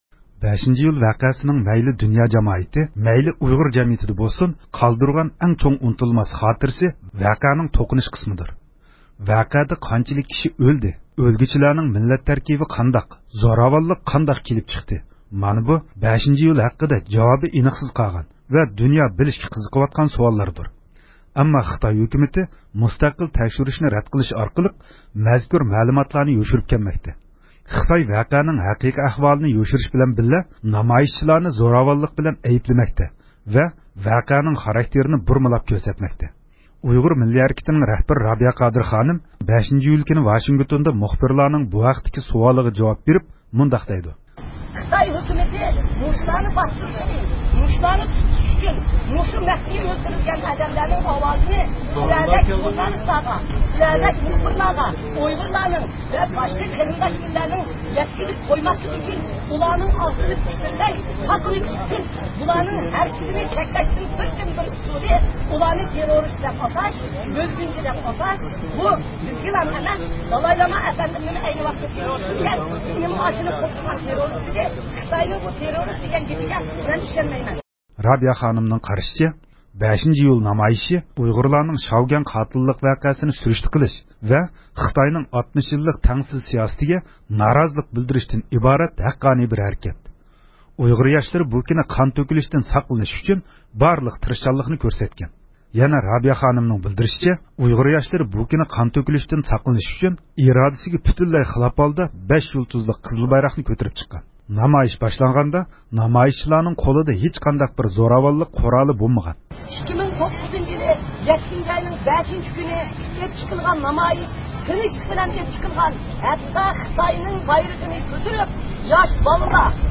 رابىيە قادىر خانىمنىڭ «5 – ئىيۇل»نى خاتىرىلەش نامايىشىدا مۇخبىرلارغا بەرگەن جاۋابلىرى – ئۇيغۇر مىللى ھەركىتى
ئۇيغۇر مىللىي ھەرىكىتىنىڭ رابىيە قادىر خانىم، نامايىش كۈنى مۇخبىرلارنىڭ بۇ ھەقتىكى سوئالىغا جاۋاب بەردى.